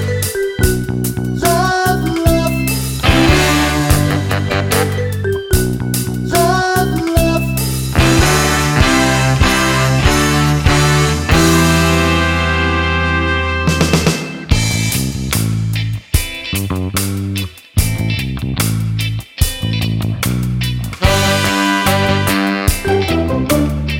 Minus Piano Ska 3:22 Buy £1.50